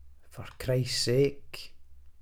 glaswegian
scottish